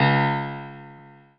piano-ff-17.wav